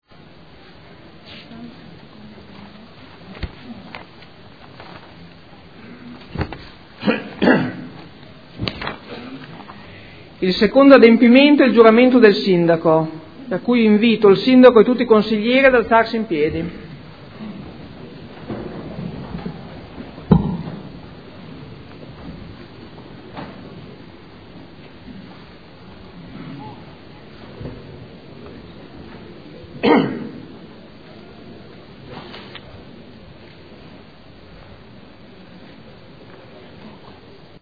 Seduta del 21/06/2014. Introduce Ordine del Giorno avente per oggetto "Giuramento del Sindaco"